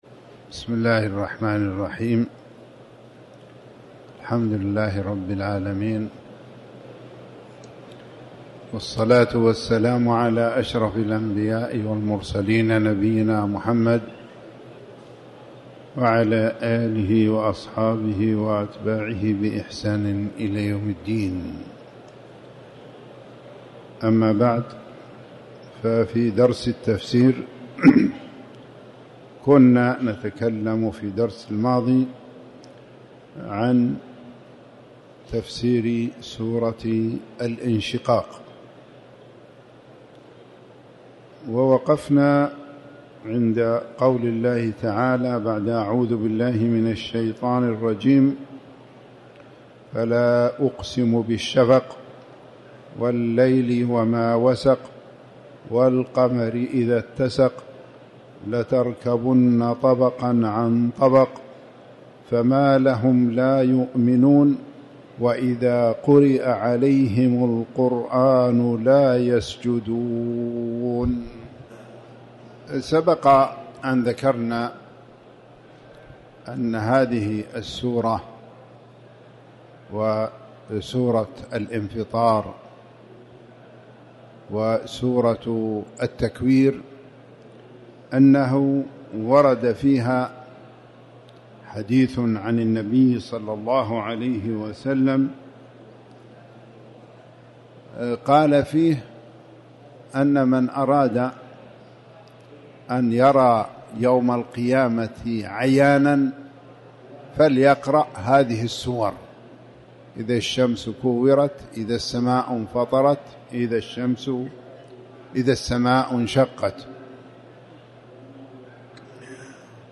تاريخ النشر ١ رمضان ١٤٤٠ هـ المكان: المسجد الحرام الشيخ